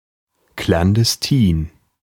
Ääntäminen
IPA : /klænˈdɛstɪn/